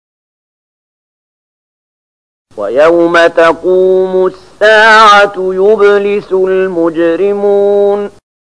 030012 Surat Ar-Ruum ayat 12 dengan bacaan murattal ayat oleh Syaikh Mahmud Khalilil Hushariy: